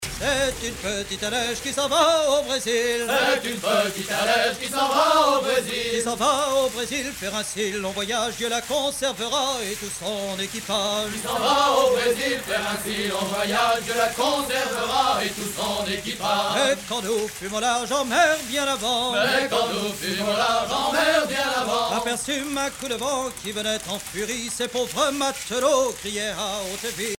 Genre strophique
Catégorie Pièce musicale éditée